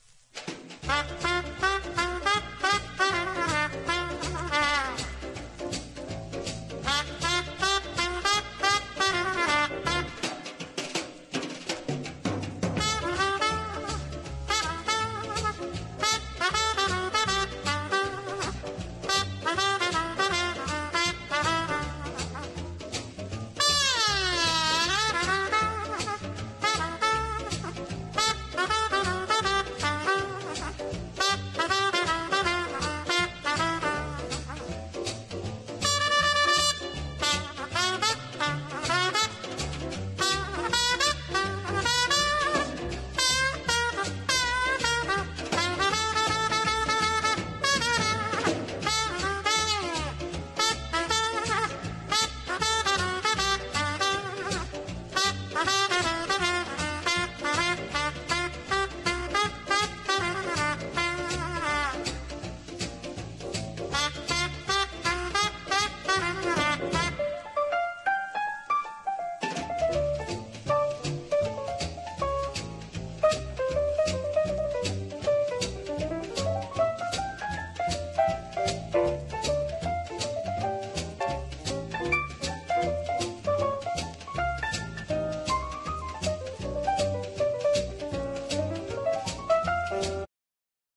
VOCAL JAZZ# BIGBAND / SWING
ニューオーリンズ・ジャズの流れを汲むスウィング・トランペッター